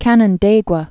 (kănən-dāgwə)